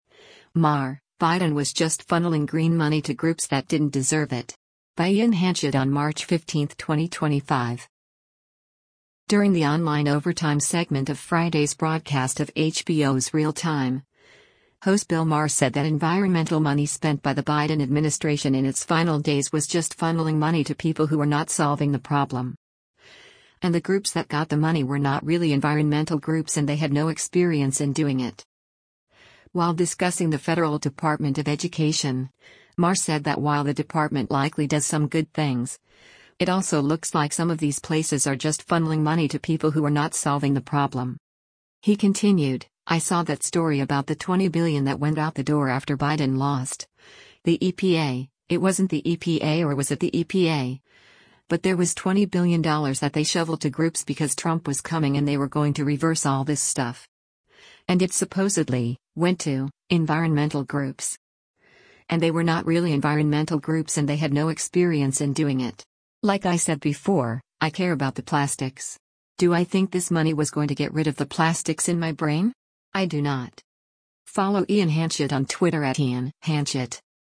During the online “Overtime” segment of Friday’s broadcast of HBO’s “Real Time,” host Bill Maher said that environmental money spent by the Biden administration in its final days was “just funneling money to people who are not solving the problem.”